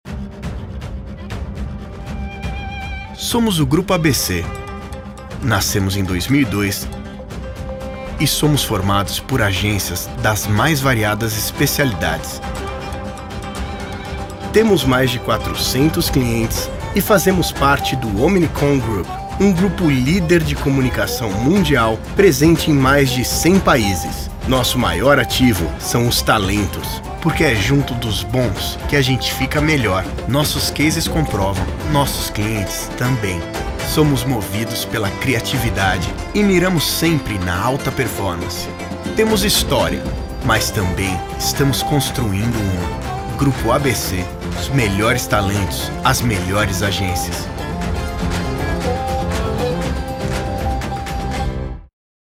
Narração
Tenho um home studio de nível profissional.
Jovem adulto